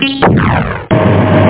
Amiga 8-bit Sampled Voice
1 channel
sample01.boing_.mp3